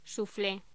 Locución: Suflé
voz palabra sonido soufflé